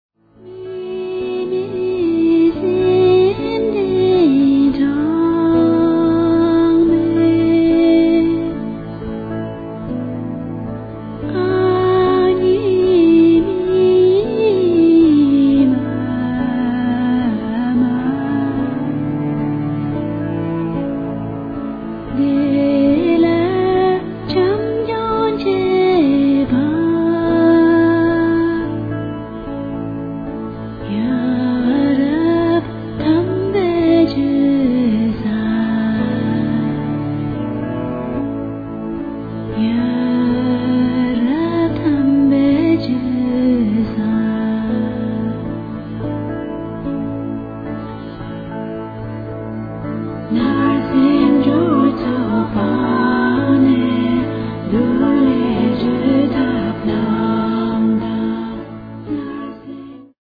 Tibetische Mantren-Musik für den Weltfrieden.